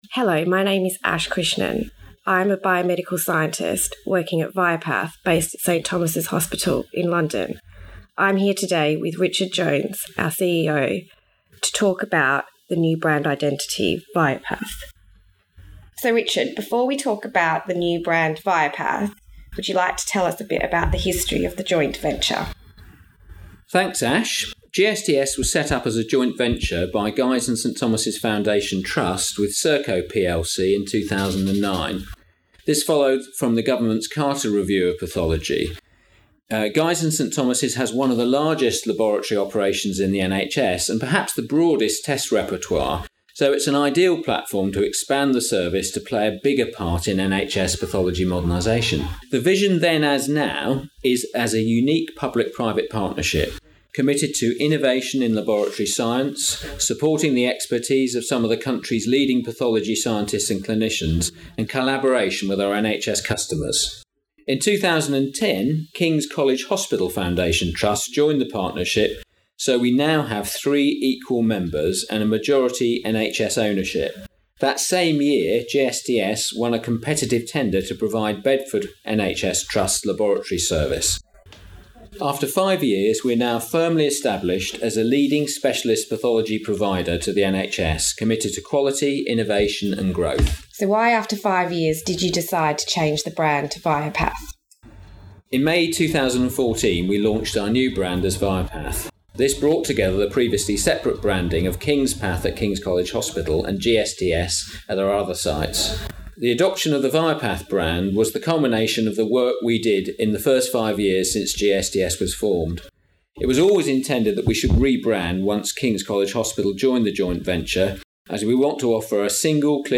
Viapath Interview v_final edit_no noise reduction.mp3